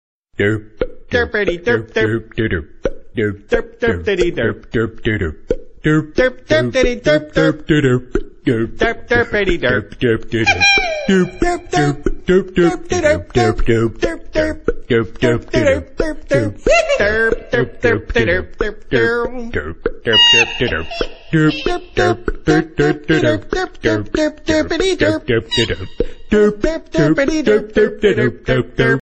Акапельно